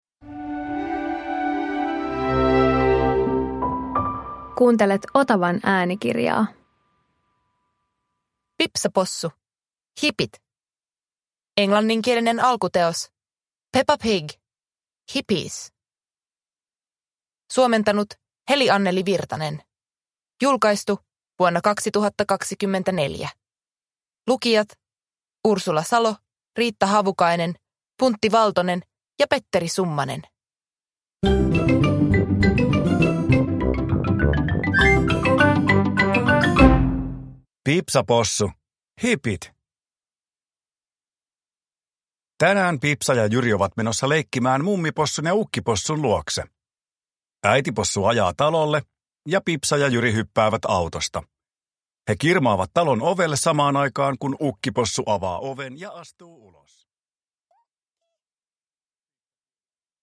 Pipsa Possu - Hipit – Ljudbok